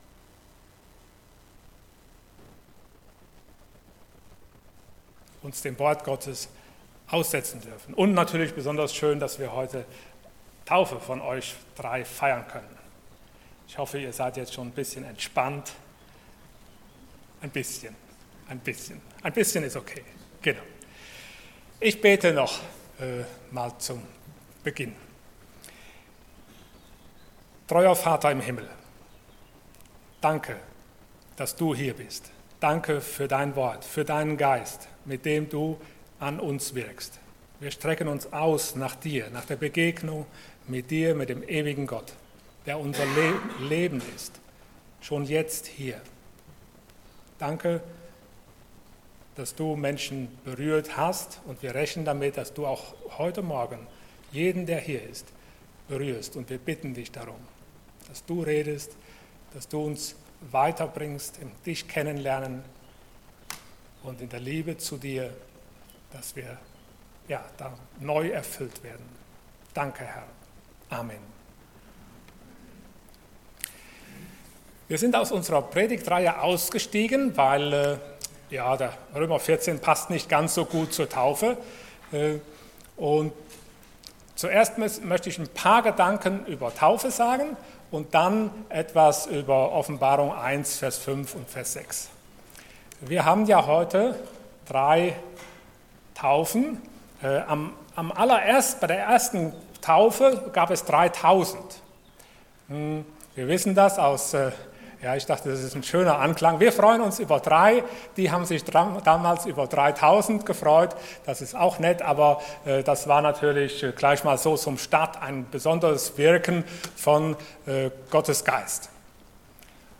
Taufe 2024 Passage: Offenbarung 1,5-6 Dienstart: Sonntag Morgen Geliebt und noch viel mehr Themen